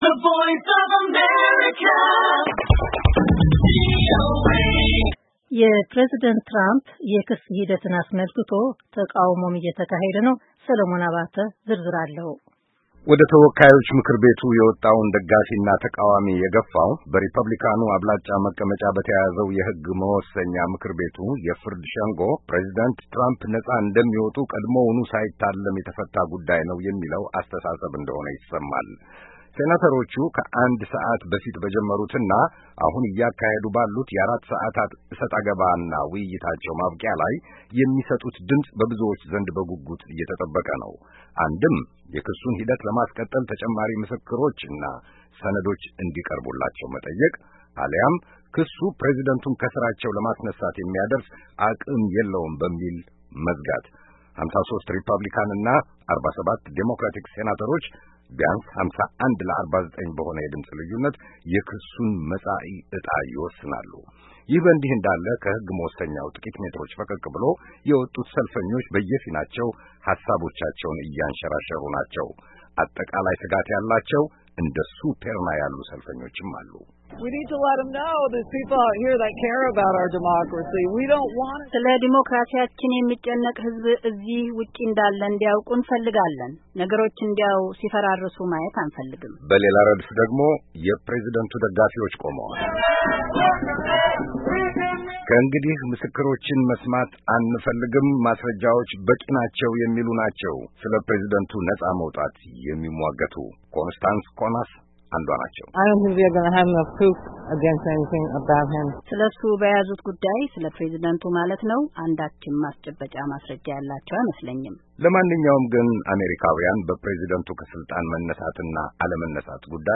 ክሱ እየታየ ባለበት የተወካዮች ምክርቤቱ ደጃፍ ላይ የወጡ ደጋፊና ተቃዋሚ ሰልፈኞችን ተመልክታለች ብዙዎቹንም አነጋገራለች።